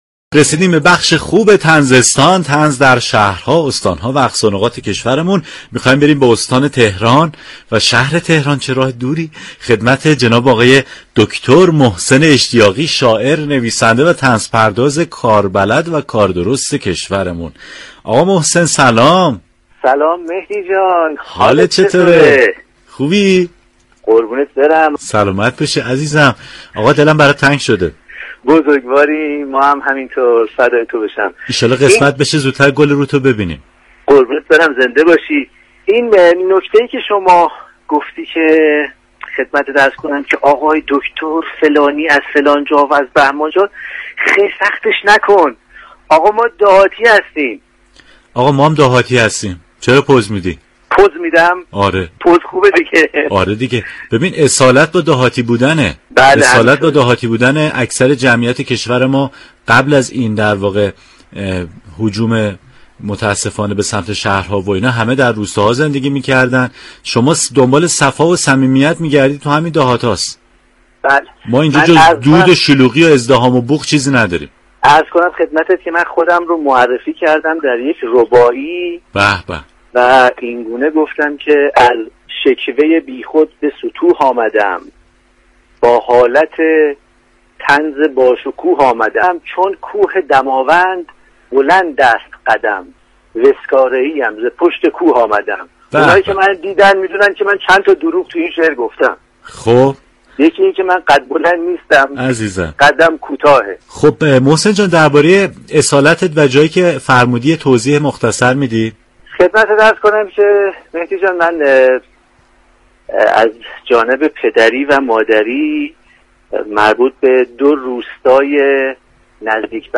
شنونده گفتگوی رادیو صبا